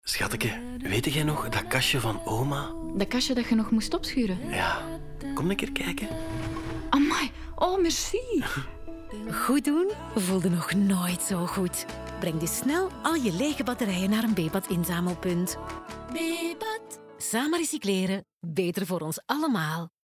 Sound Production & Sound Design: La Vita Studios
250324-Bebat-radio-mix-OLA--23LUFS-meubel-NL-20.mp3